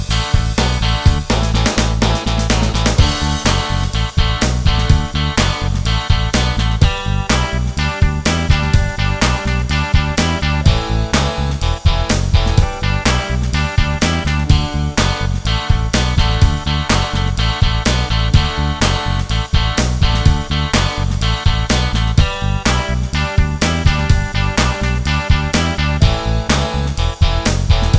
Wersja instrumentalna jest bez linii melodycznej. https